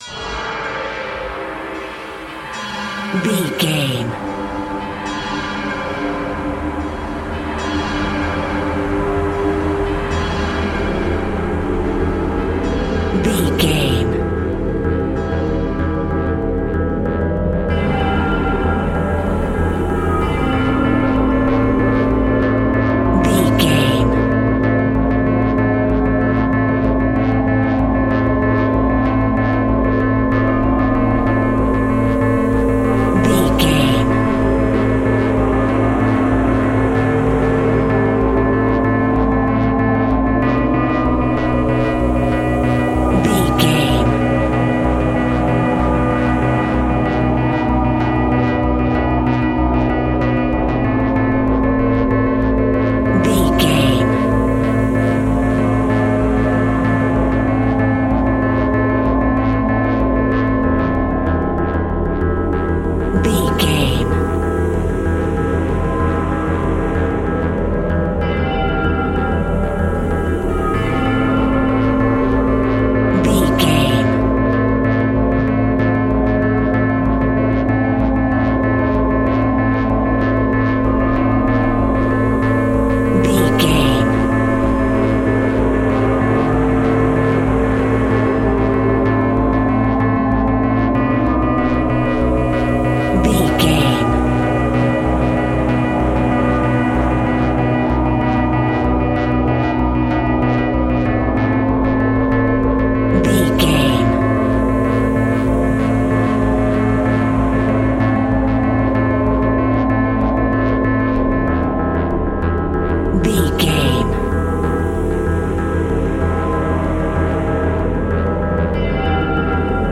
Aeolian/Minor
ominous
dark
eerie
synthesiser
horror music
Horror Pads
Horror Synths